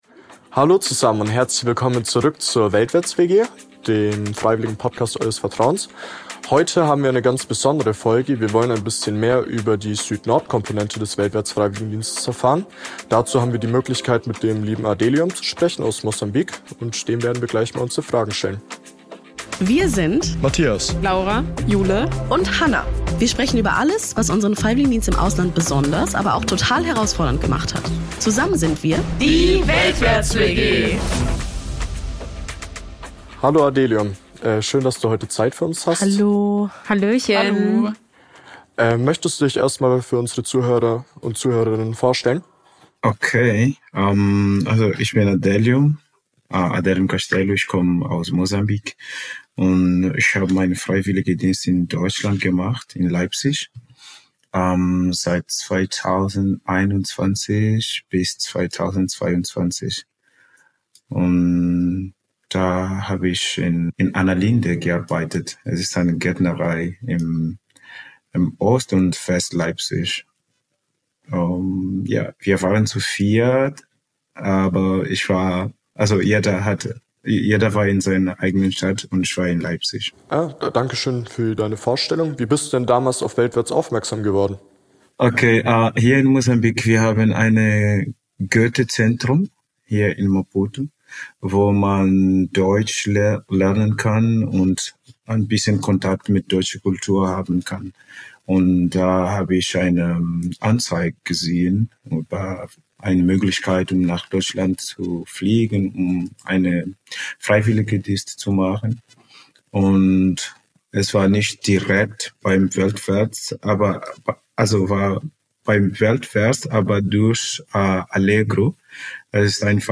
Insider-Talk